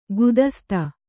♪ gudasta